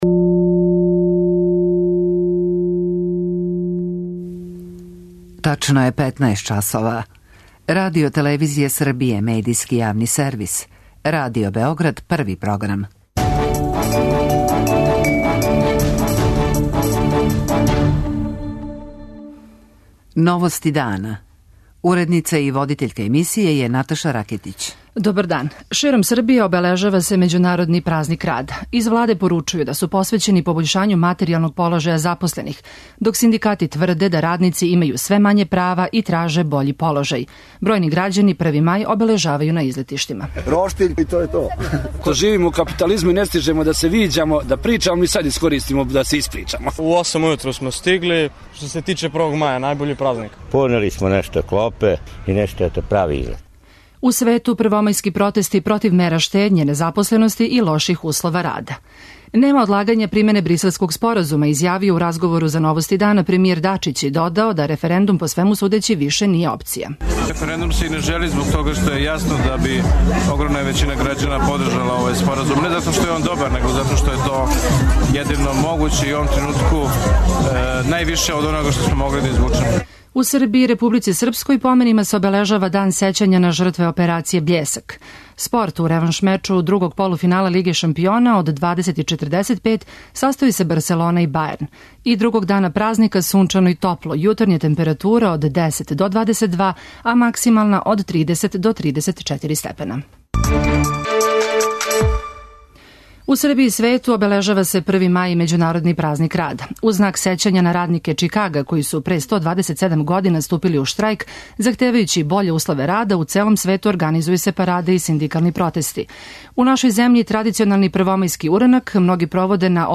Премијер Ивица Дачић у интервјуу за Новости дана говори да ће споразум о нормализацији односа Београда и Приштине бити примењен, иако га Срби са севера Косова не прихватају.